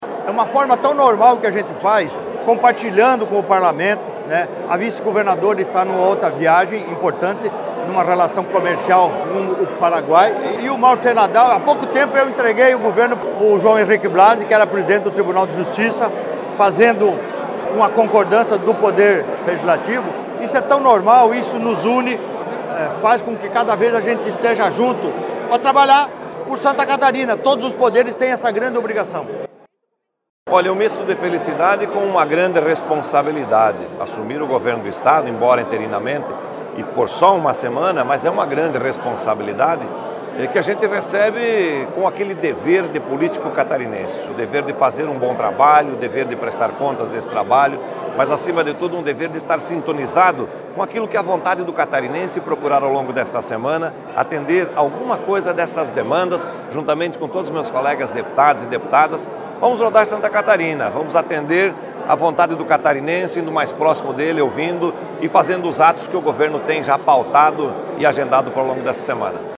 SECOM-Sonoras-Transmissao-do-cargo-de-governador-ao-presidente-da-Alesc.mp3